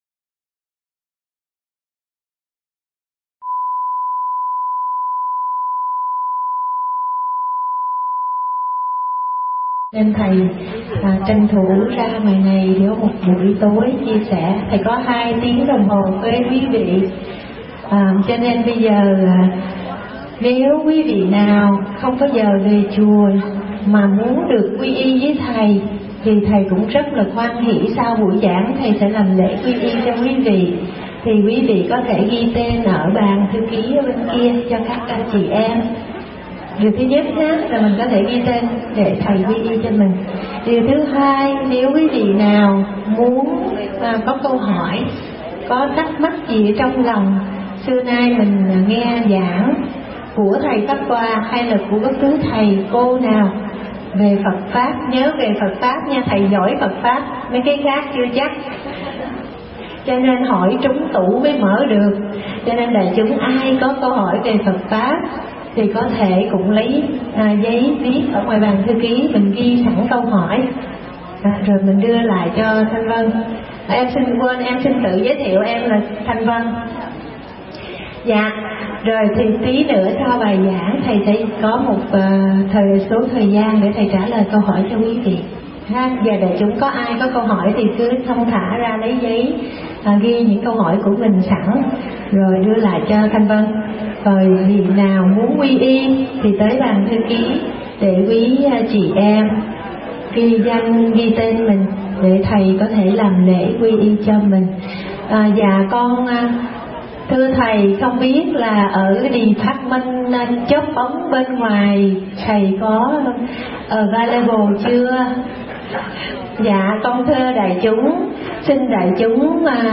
Tải mp3 Thuyết Pháp Thiện Căn Phước Đức Nhân Duyên – Đại Đức Thích Pháp Hòa thuyết giảng tại Garland Texas ngày 16 tháng 4 năm 2015